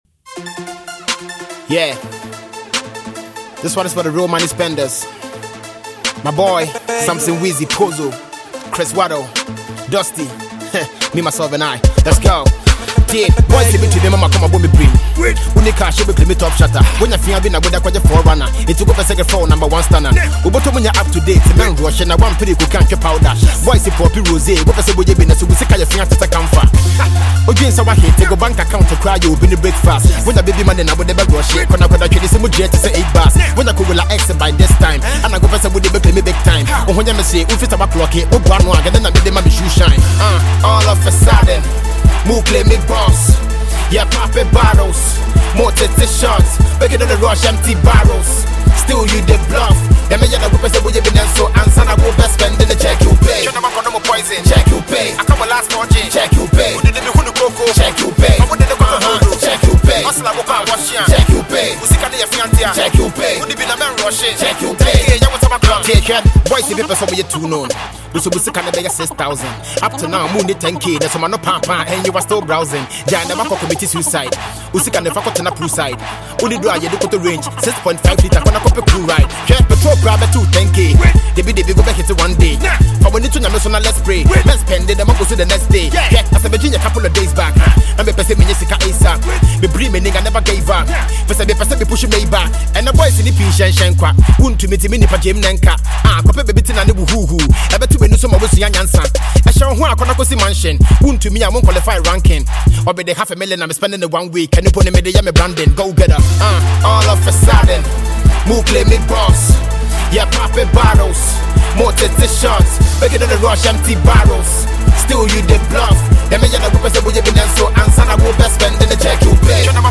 Award-winning Ghanaian rapper
hip-hop banger